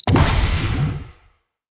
Pickup Jetpack Launch Sound Effect
Download a high-quality pickup jetpack launch sound effect.
pickup-jetpack-launch-1.mp3